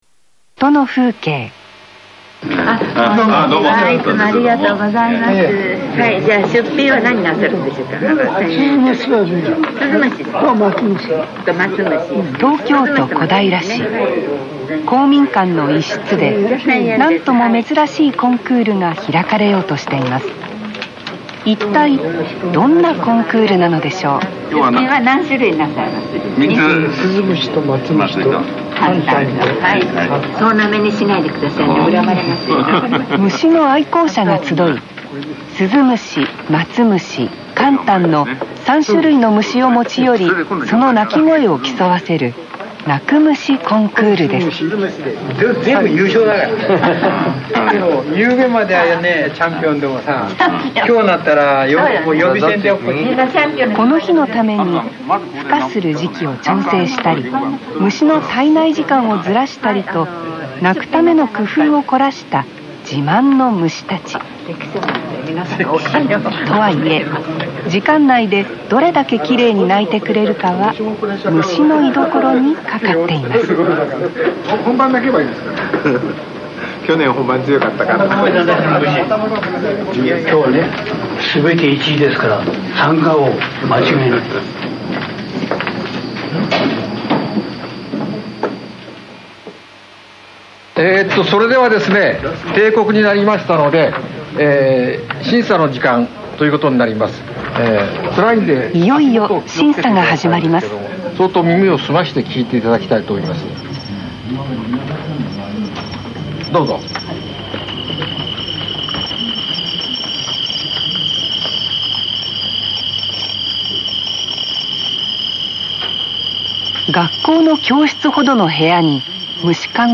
先般開催のコンクールの時にNHKが取材した音の風景が次の通り12回放送されます。